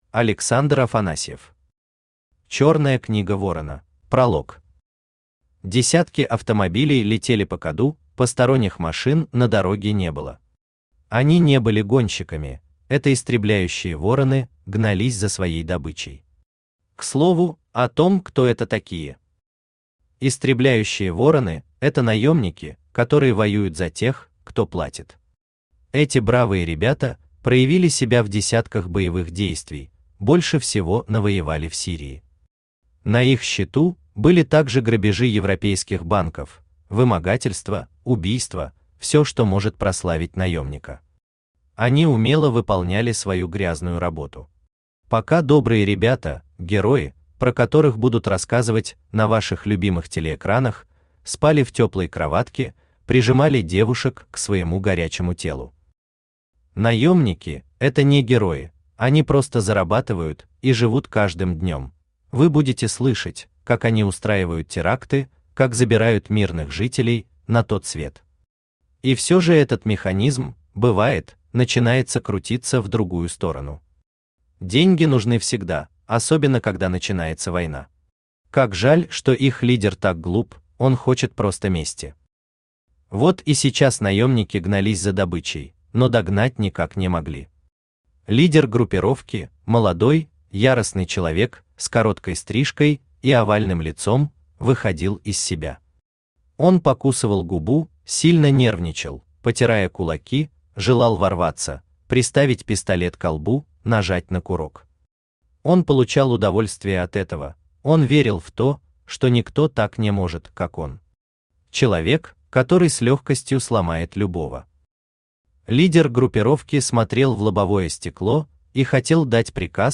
Аудиокнига Чёрная книга ворона | Библиотека аудиокниг
Aудиокнига Чёрная книга ворона Автор Александр Константинович Афанасьев Читает аудиокнигу Авточтец ЛитРес.